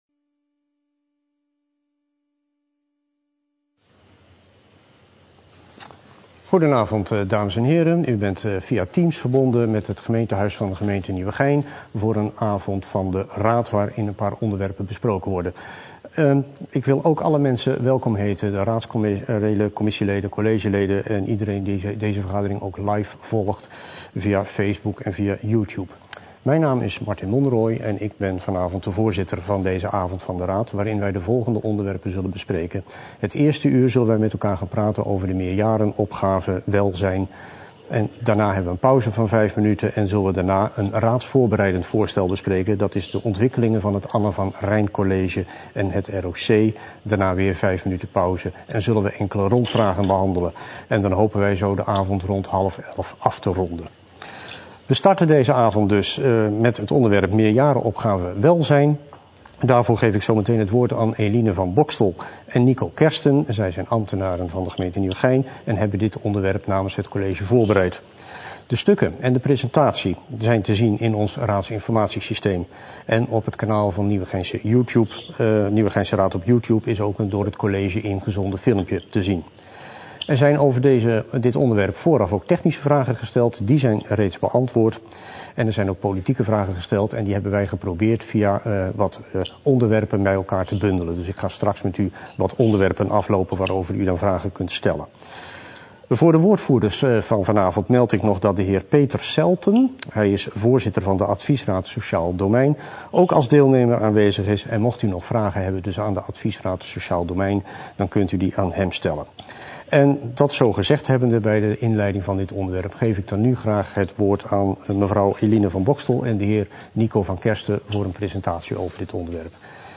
Online, te volgen via livestreaming